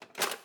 Babushka / audio / sfx / Kitchen / SFX_Cutlery_02.wav
SFX_Cutlery_02.wav